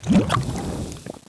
c_slime_bat2.wav